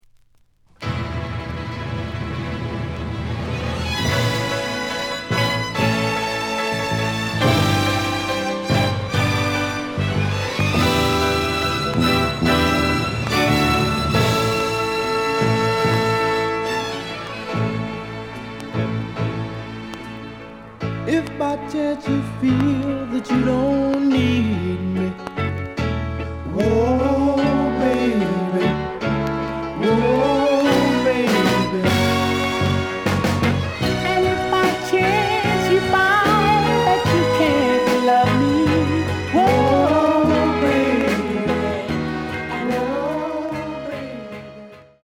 The audio sample is recorded from the actual item.
●Genre: Soul, 60's Soul
Some click noise on A side due to scratches.